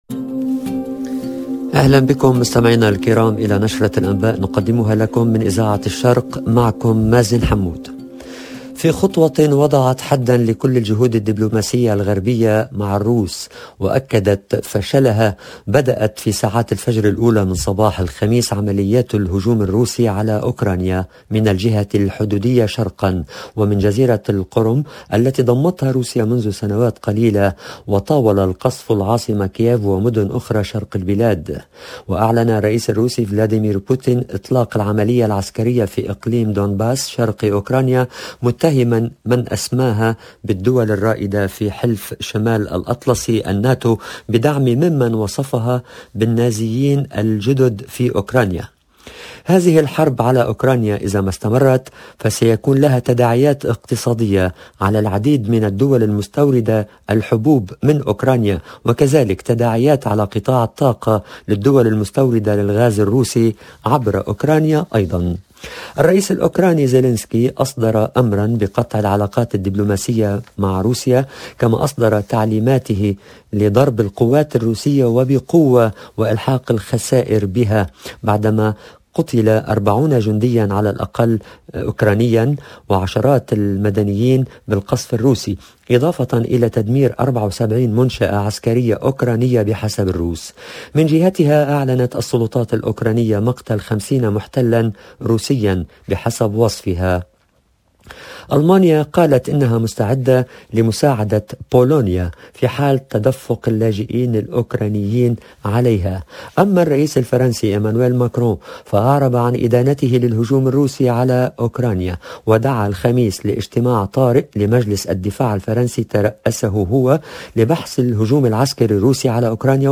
LE JOURNAL DU SOIR EN LANGUE ARABE DU 24/02/22